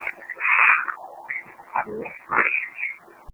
during an investigation in a cemetery.